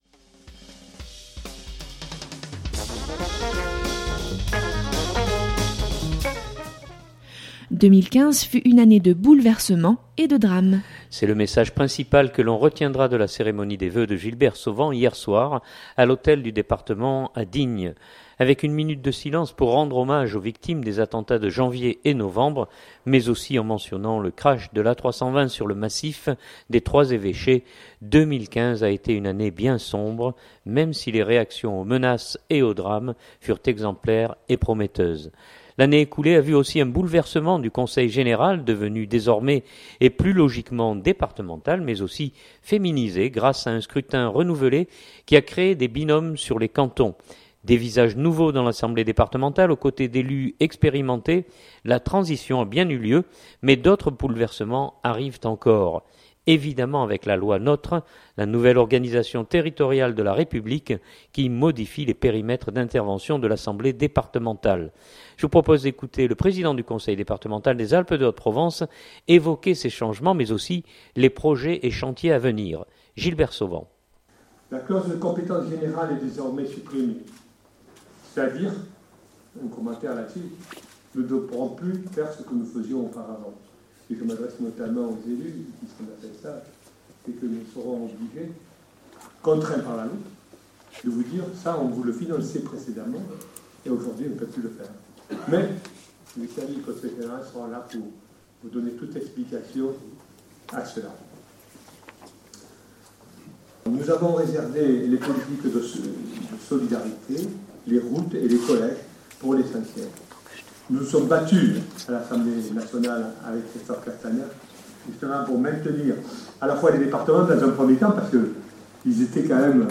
C’est le message principal que l’on retiendra de la cérémonie des vœux de Gilbert Sauvan hier soir à l’hôtel du département à Digne.
Evidemment avec la loi NOTRe, la nouvelle organisation territoriale de la République qui modifie les périmètres d’intervention de l’assemblée départementale. Je vous propose d’écouter le Président du Conseil départemental des Alpes de Haute-Provence évoquer ces changements, mais aussi les projets et chantiers à venir.